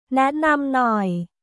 ネンナム ノイ